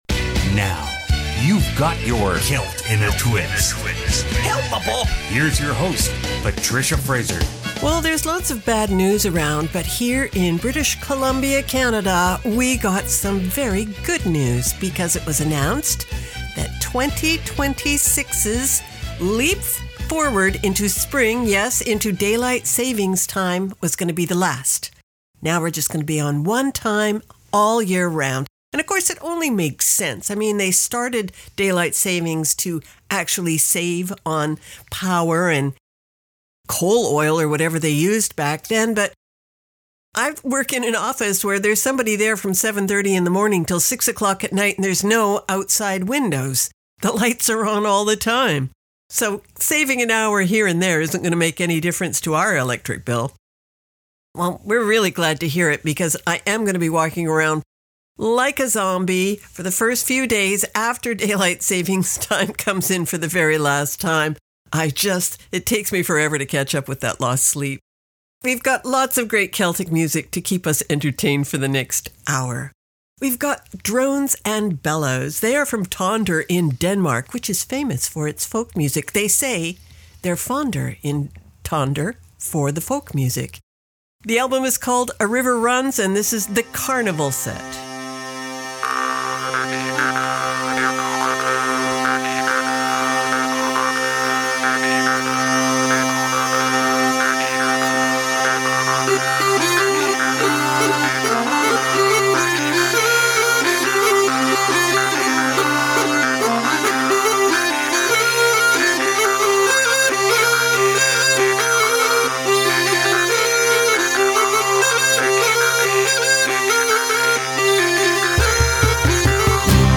Canada's Contemporary Celtic Radio Hour Program Type: Music